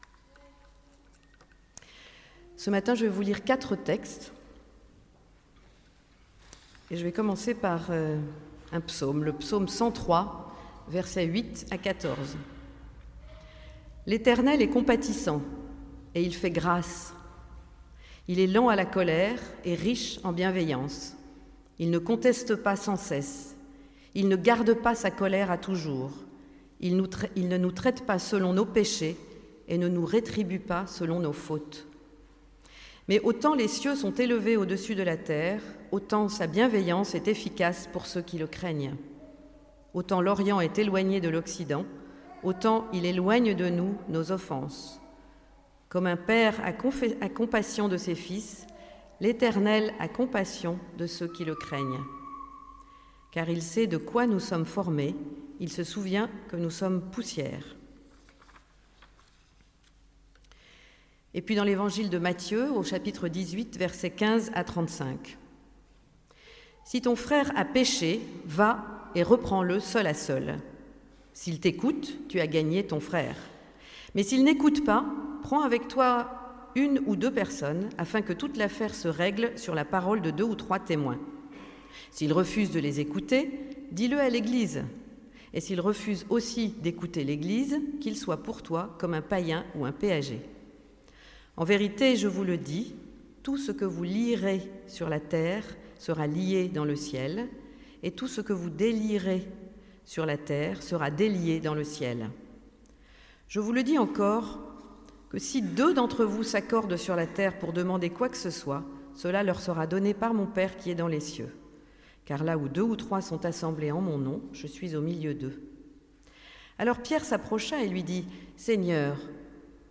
Prédication du 9 décembre 2018